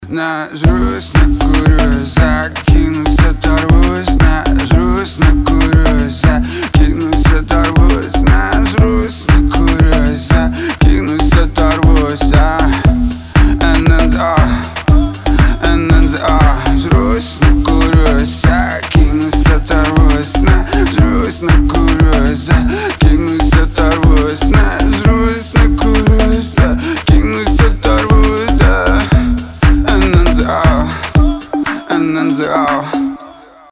• Качество: 320, Stereo
клубняк